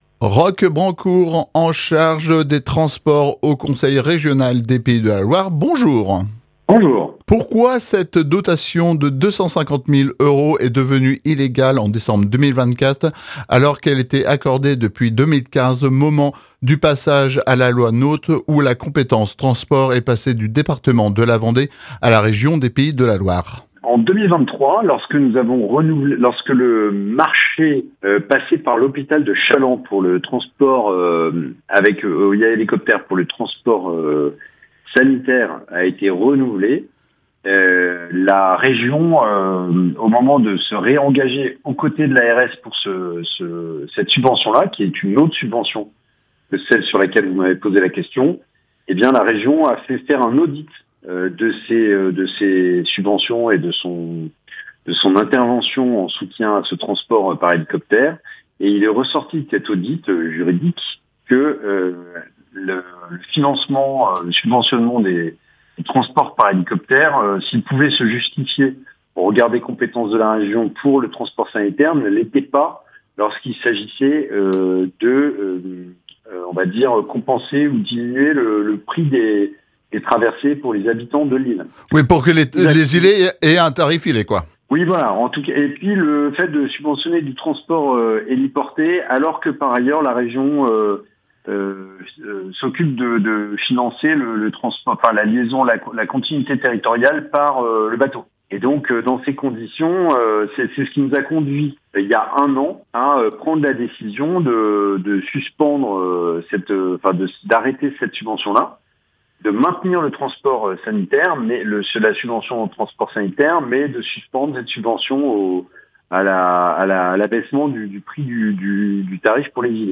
Après le rejet de l’amendement visant à maintenir la subvention régionale, Roch Brancour, vice-président de la Région des Pays de la Loire en charge des transports, répond à nos questions sur l’avenir de la liaison par hélicoptère entre l’Île d’Yeu et le continent. Dans cette interview, il revient sur la position de la Région, les compétences en matière de transport et les raisons de cette décision.